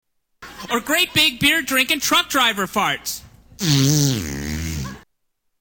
Fart 2